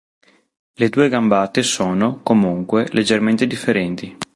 co‧mùn‧que
/koˈmun.kwe/